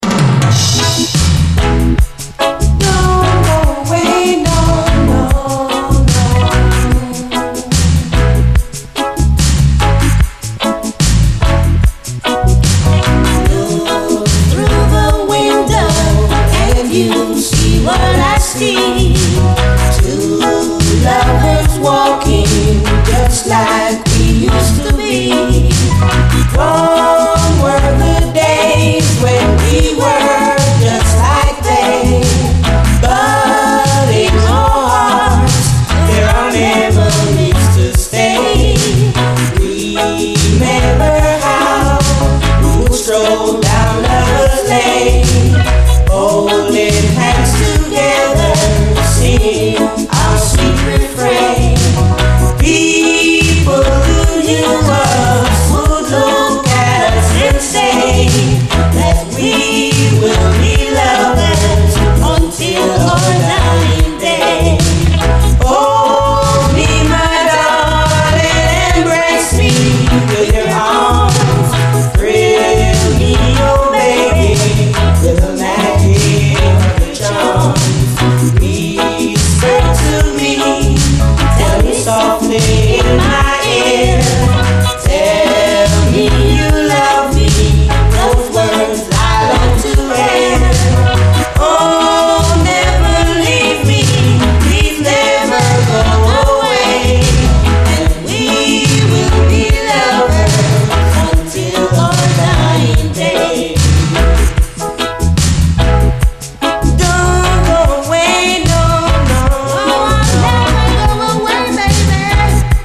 延々と続く鬼メロウなブレイク、そして艶やかなギター・フレーズが有名なクラシック！